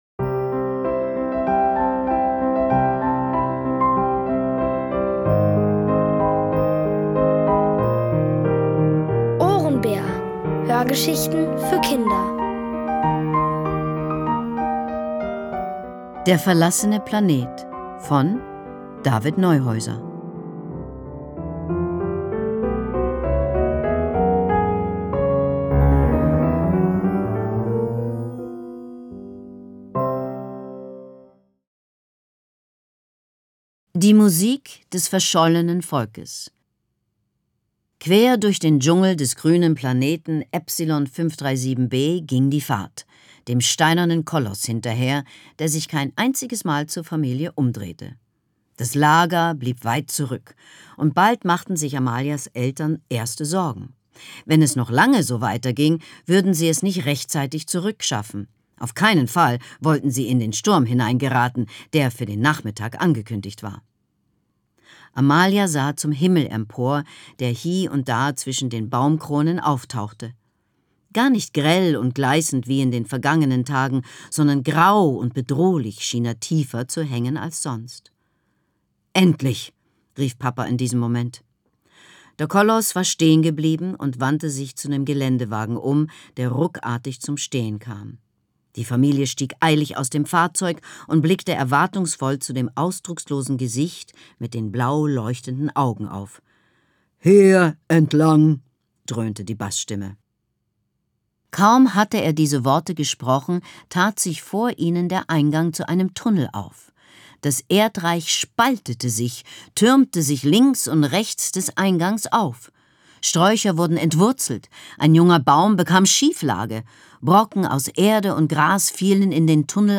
Es liest: Leslie Malton.